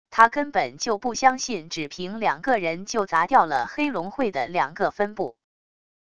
它根本就不相信只凭两个人就砸掉了黑龙会的两个分部wav音频生成系统WAV Audio Player